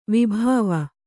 ♪ vibhāva